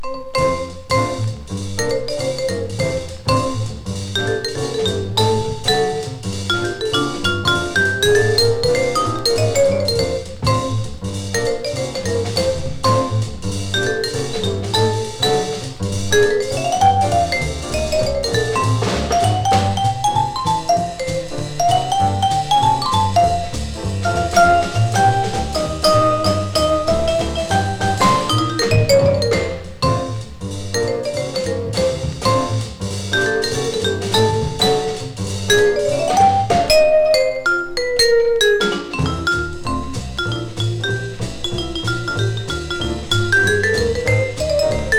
Jazz　USA　10inchレコード　33rpm　Mono